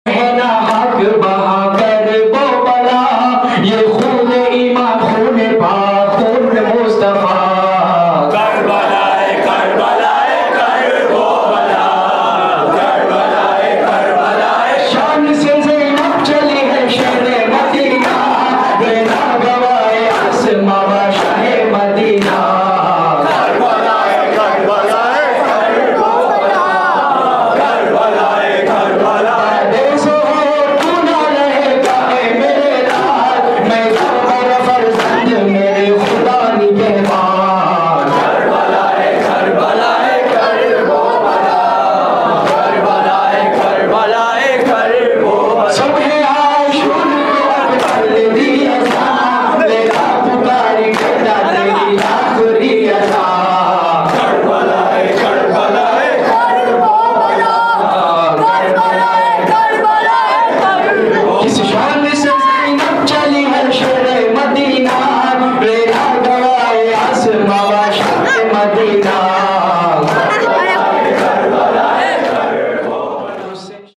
Submit lyrics, translations, corrections, or audio for this Nawha.